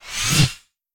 whooshForth_Farthest2.wav